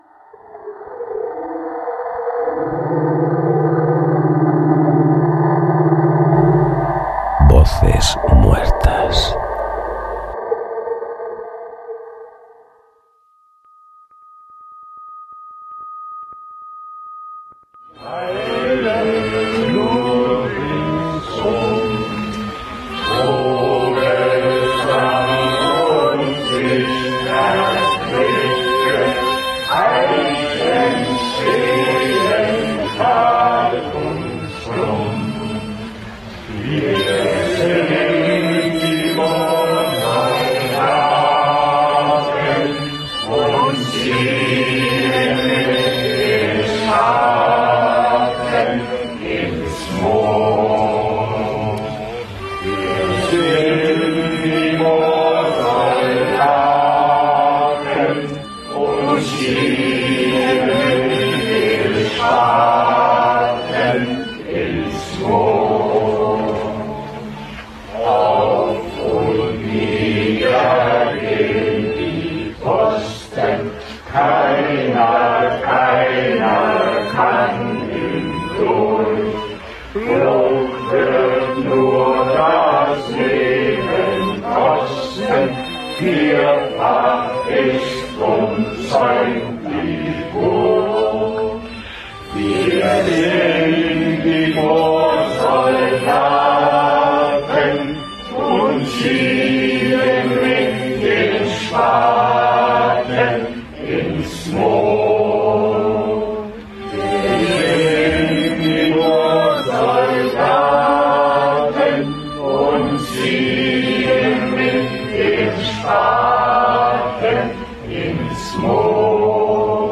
Careta del programa i mescla sonora